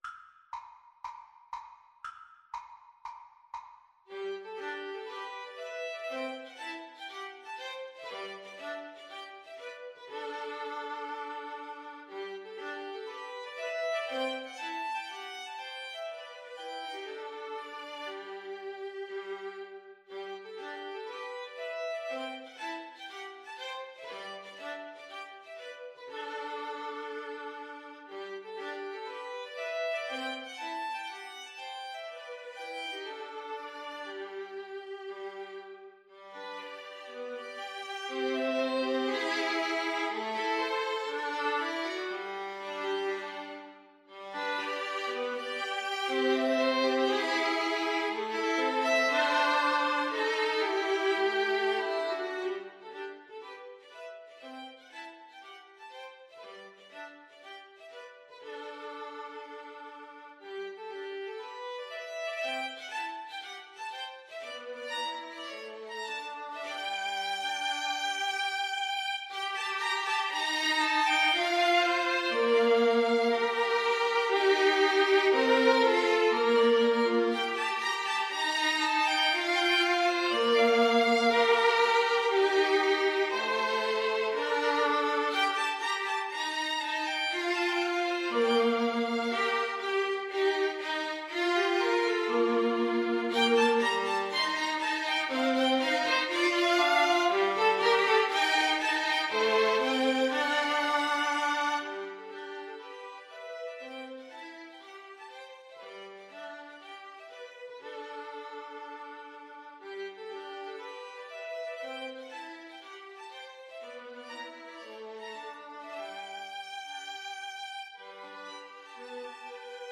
Classical Dvořák, Antonín Humoresque Op. 101, No. 7 Violin Trio version
G major (Sounding Pitch) (View more G major Music for Violin Trio )
= 60 Poco lento e grazioso